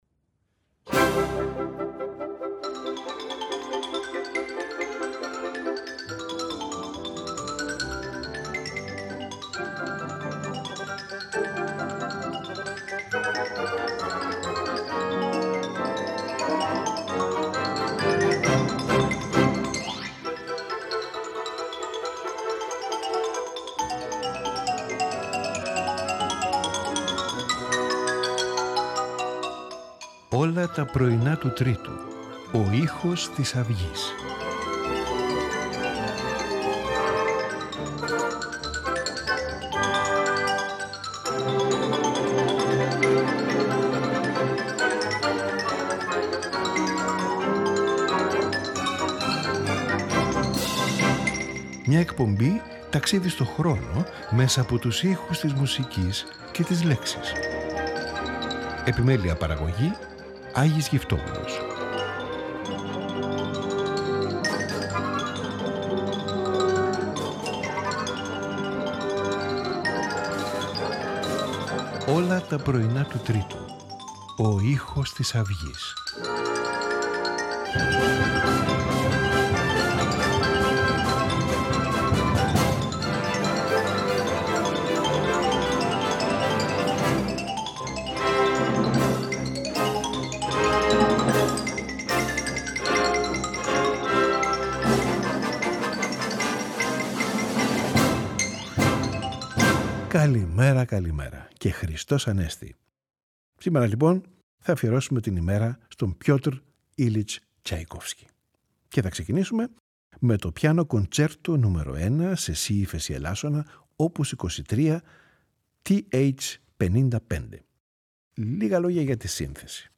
Piano concerto no 1, IN B Flat Minor, OP.23, TH.55 – Pyotr Ilyich TchaikovskyCapriccio Italien OP.45, TH47 – Pyotr Ilyich TchaikovskyNocturnal Amusement AK29 – Nikos Skalkotas